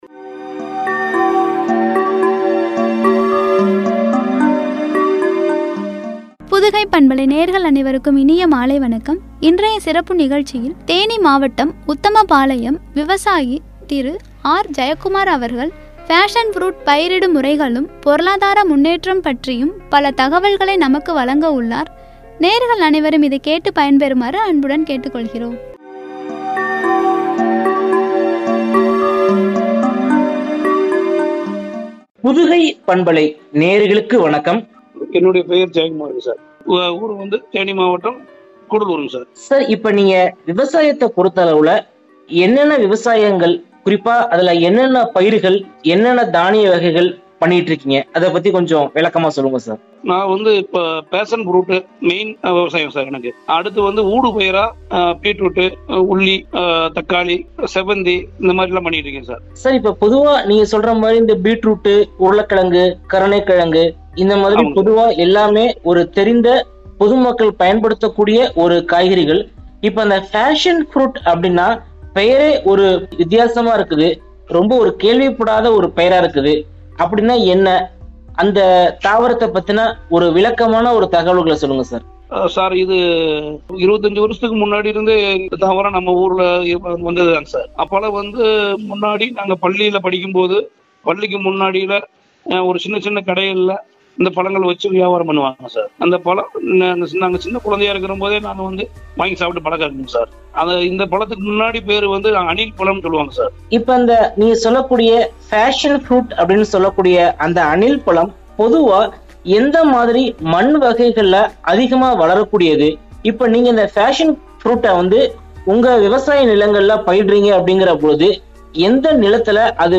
பொருளாதார முன்னேற்றமும்” குறித்து வழங்கிய உரையாடல்.